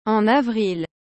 en avrilアォン アヴリル